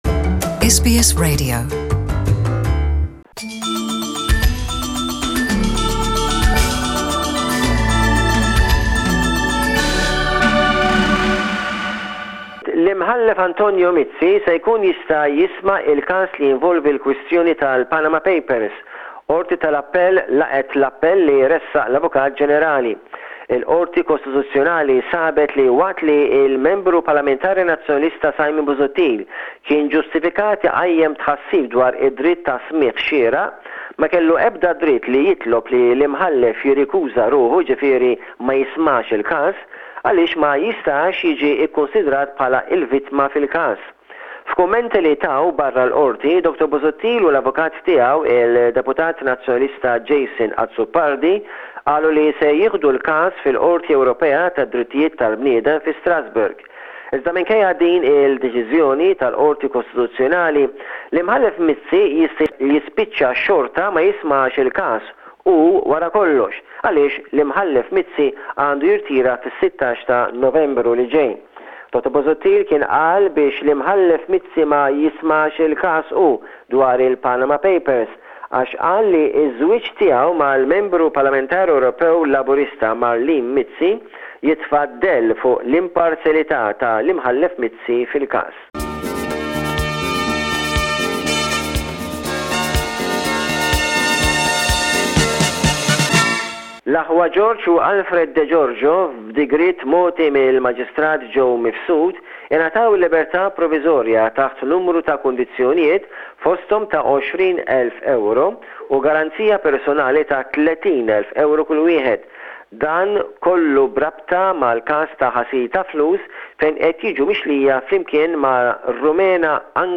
reports on the news from Malta this week.